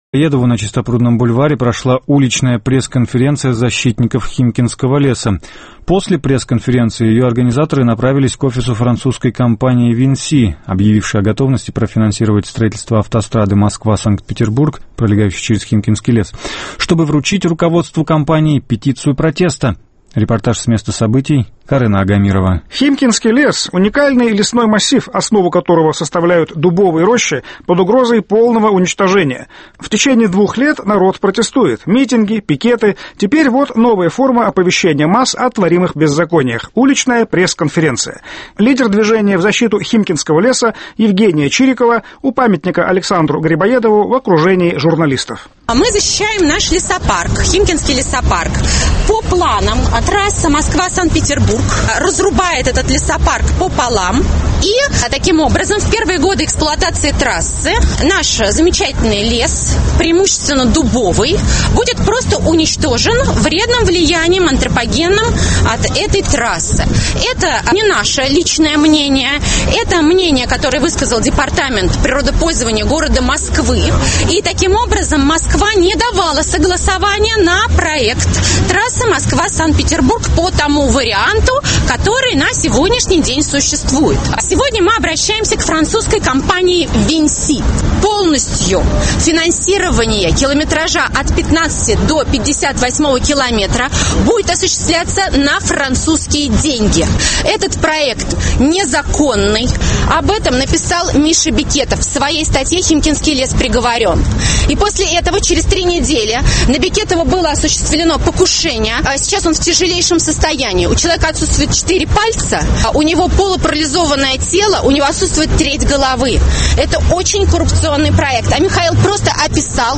В Москве прошла уличная пресс-конференция защитников Химкинского леса